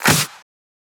edm-clap-45.wav